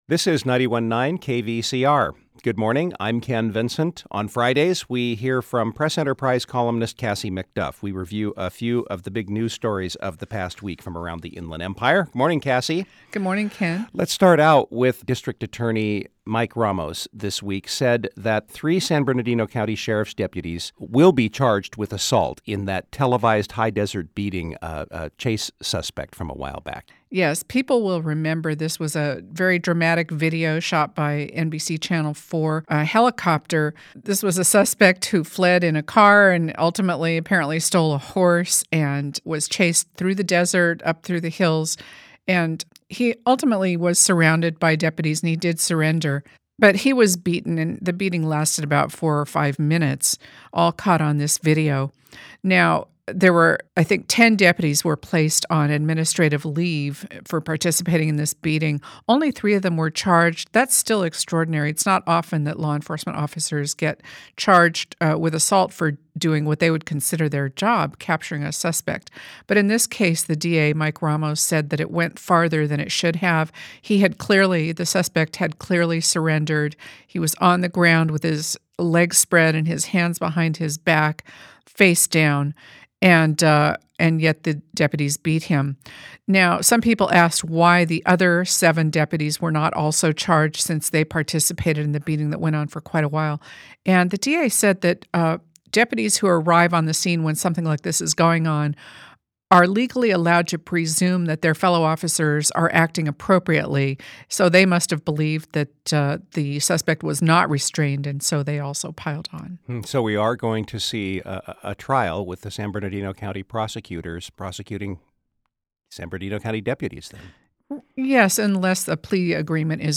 Local Civic Affairs